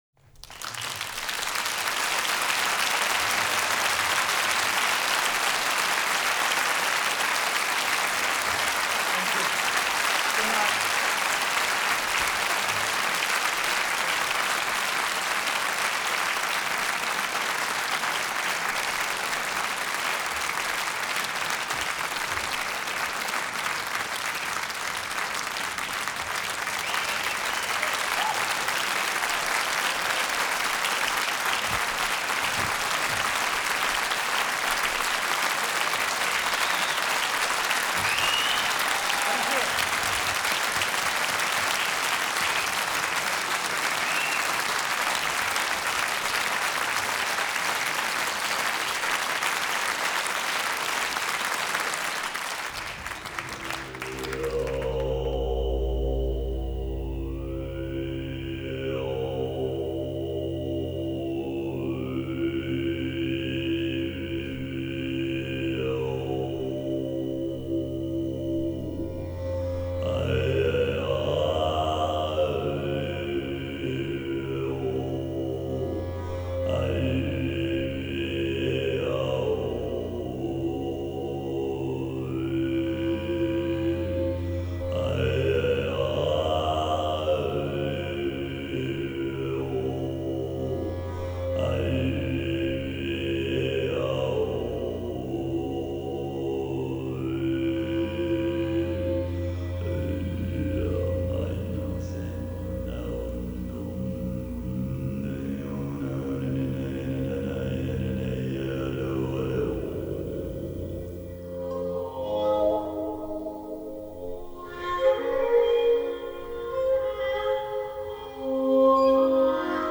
Genre: World Music
Recording: Windwood Studios